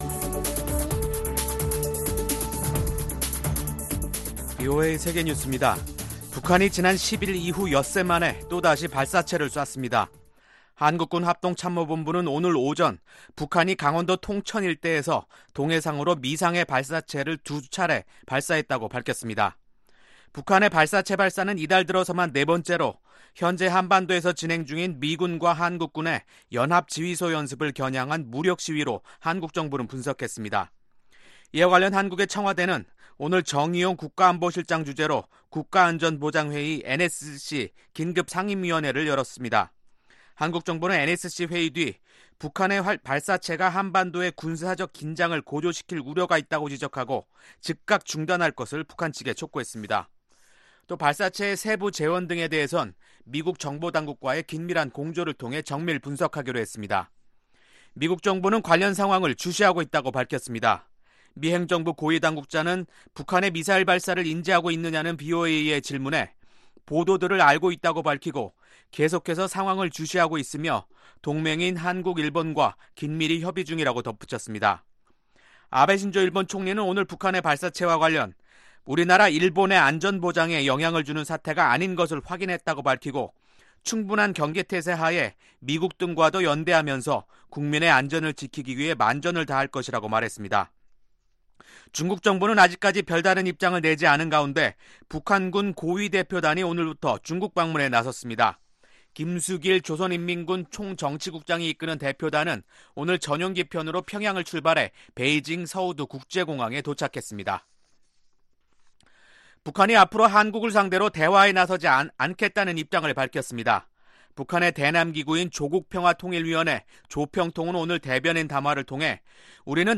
VOA 한국어 간판 뉴스 프로그램 '뉴스 투데이', 2019년 8월 15일 2부 방송입니다. 북한이 엿새 만에 또 발사체를 쏘았습니다.